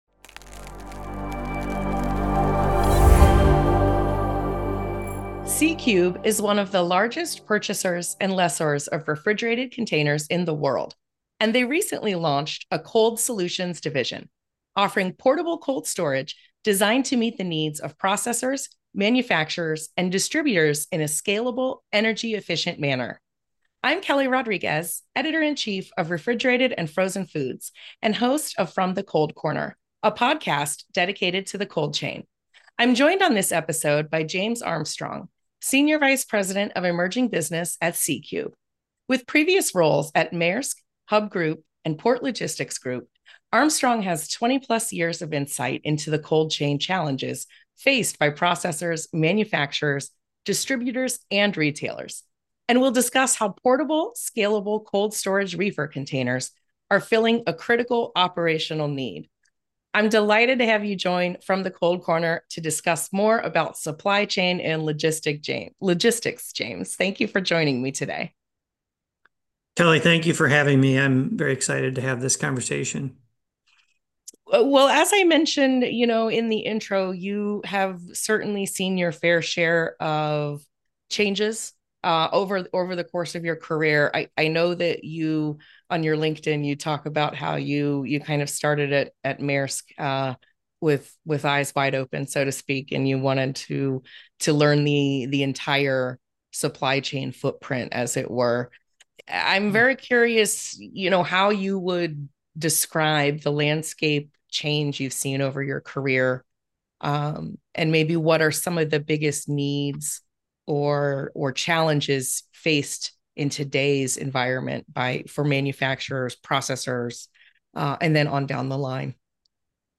Exploring Portable Cold Storage: A Conversation